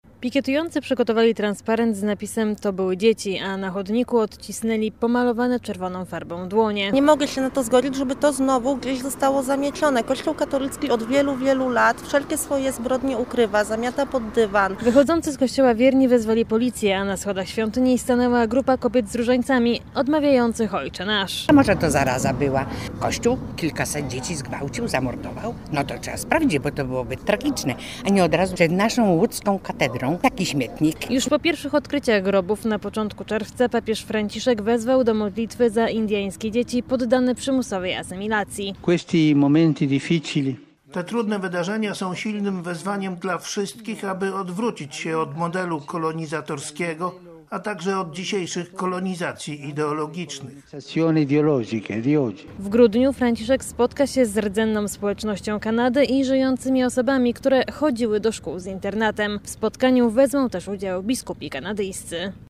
Posłuchaj relacji i dowiedz się więcej: Nazwa Plik Autor Nagłośnienie ostatnich wydarzeń w Kanadzie.